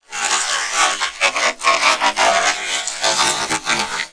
ALIEN_Communication_01_mono.wav